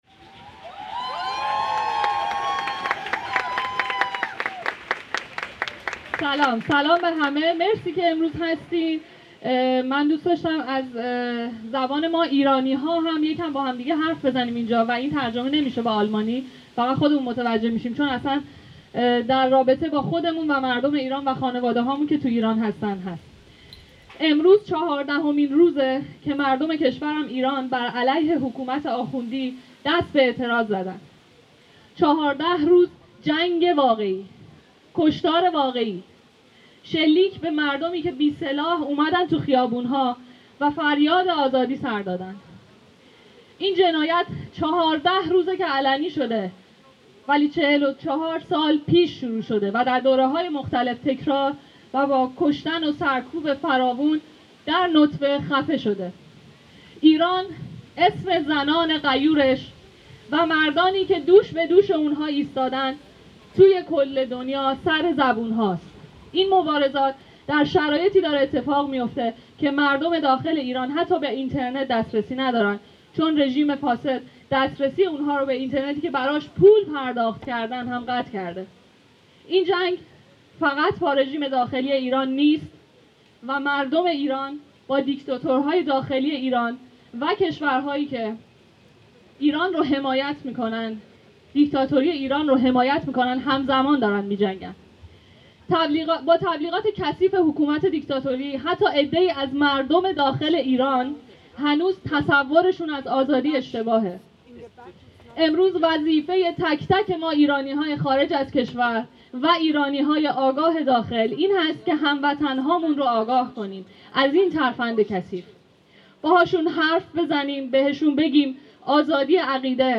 Jin-Jiyan-Azadi - Frauen-Leben-Freiheit: Kundgebung für die Freiheit des Iran in Freiburg
Anlässlich eines bundesweiten bzw. internationalen Aktionstags zur Unterstützung der Massenproteste im Iran, versammeln sich am Samstag Nachmittag, den 1.10.22, mehrere Hundert Menschen auf dem Freiburger Platz der Alten Synagoge.